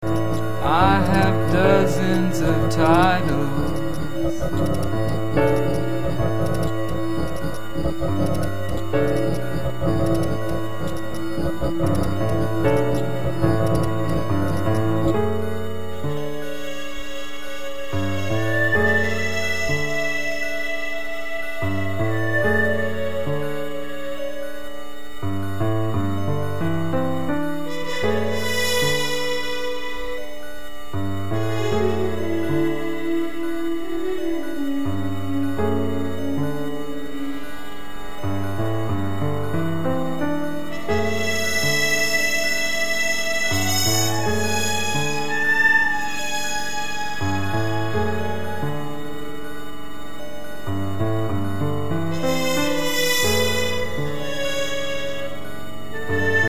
Catalogado quase sempre como pós-rock